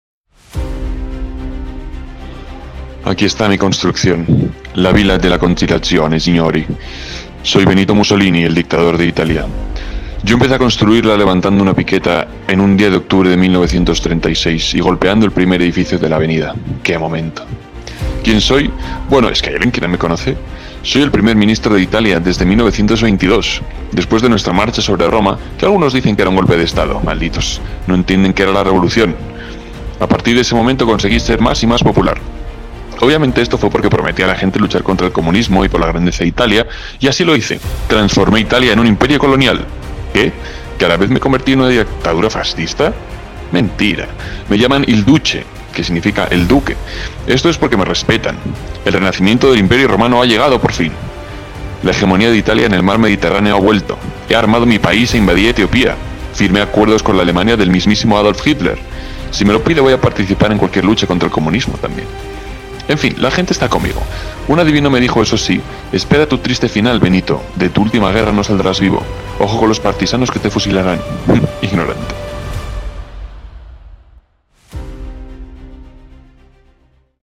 Benito Mussolini habla de si mismo